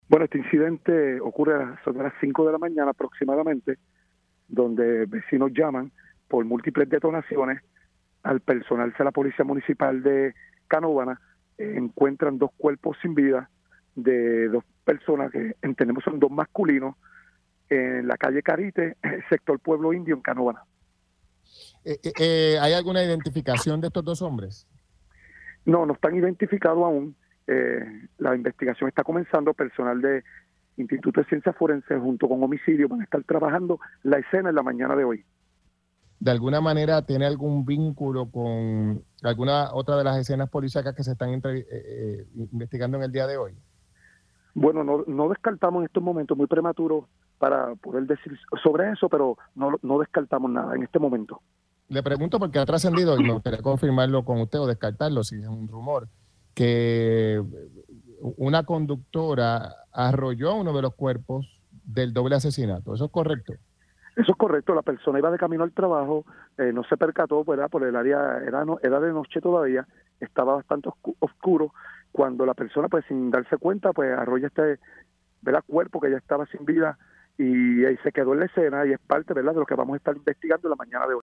de Carolina confirmó en Pega’os en la Mañana que una ciudadana arrolló uno de los cadáveres con su vehículo cuando transitaba hacia su trabajo.